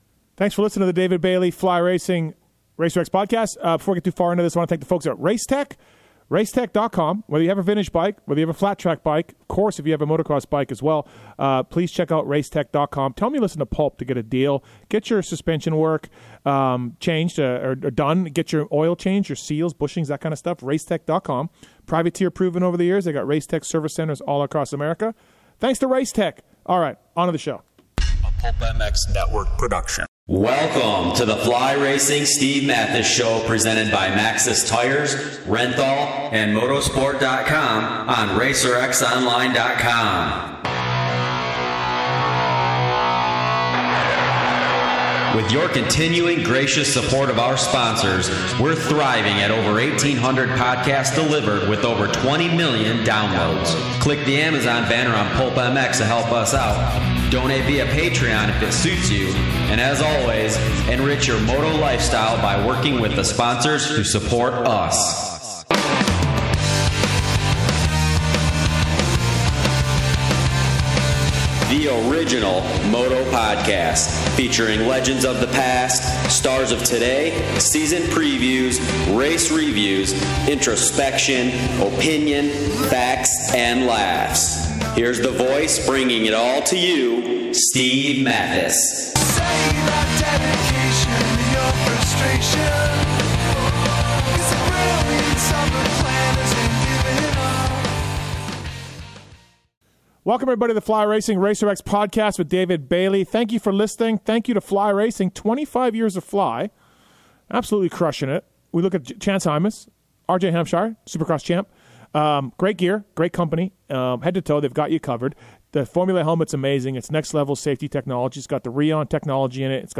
Guest: David Bailey